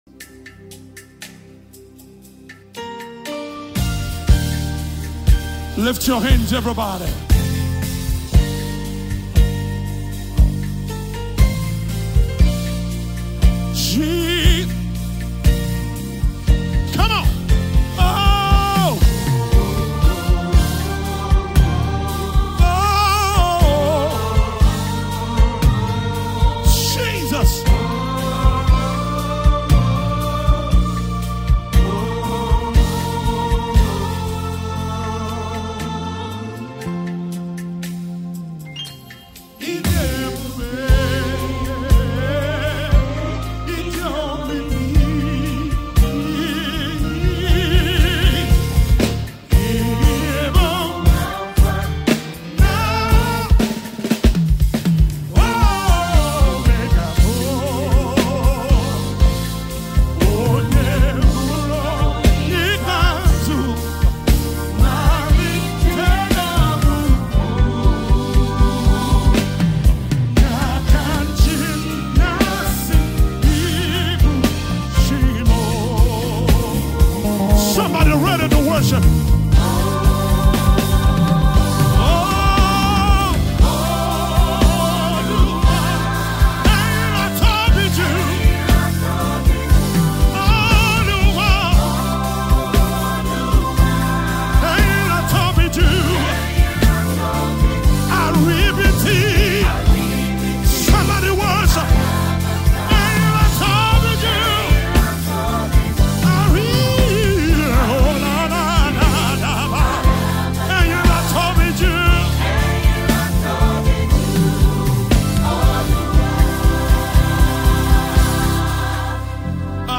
multiple award winning international Gospel music minister
Recorded live